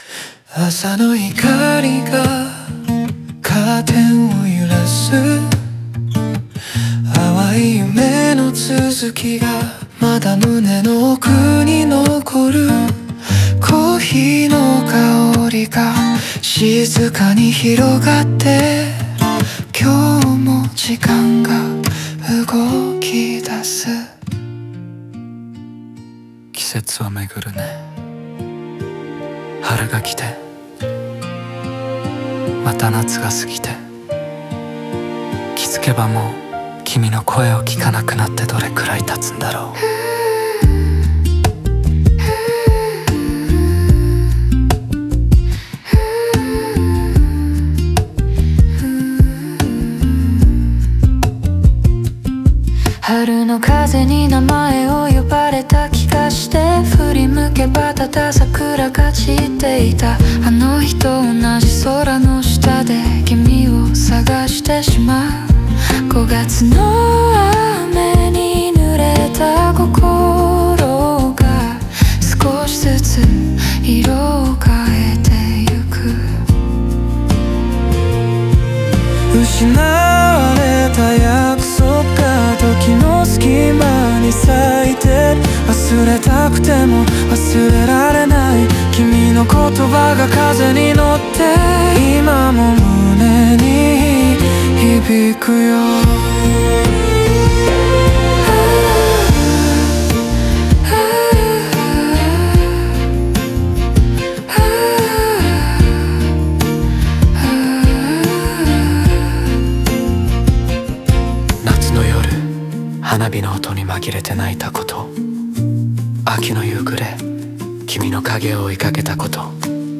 語りのようなトーンで始まり、春・夏・秋・冬の情景とともに失った愛を振り返る。